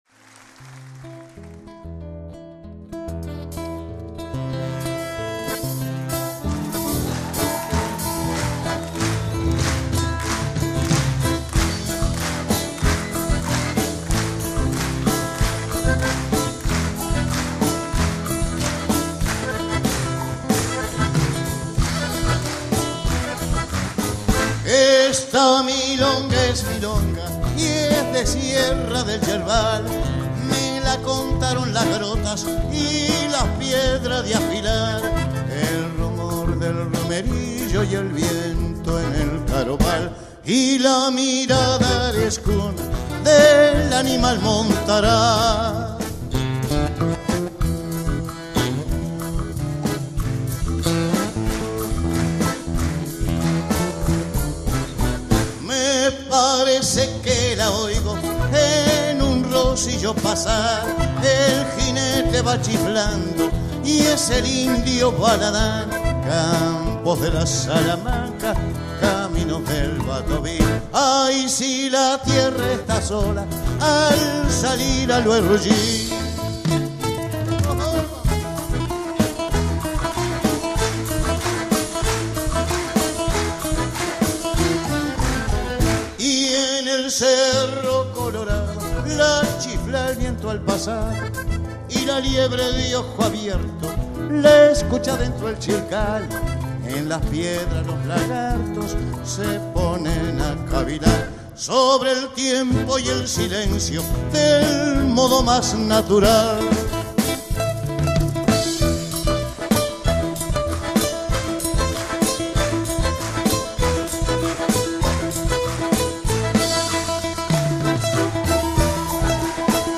Carpeta: Folklore mp3